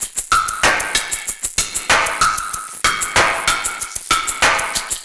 Index of /musicradar/analogue-circuit-samples/95bpm/Drums n Perc
AC_PercB_95-100.wav